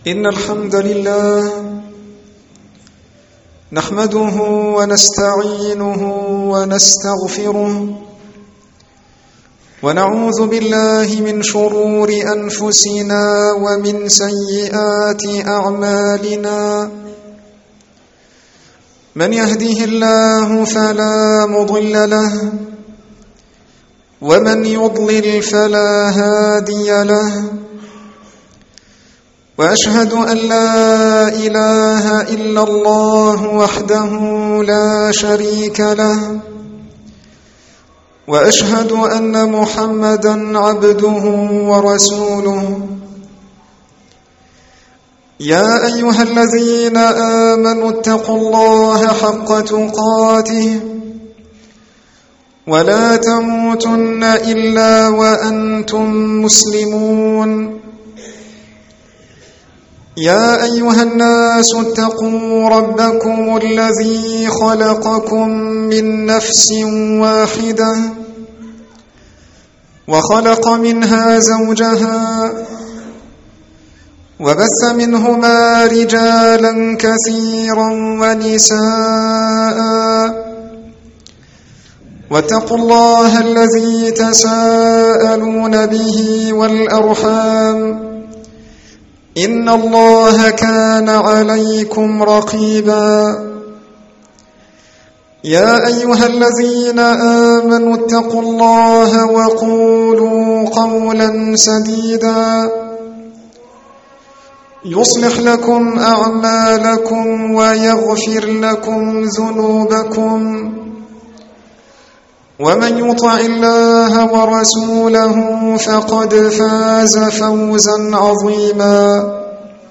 الخـطب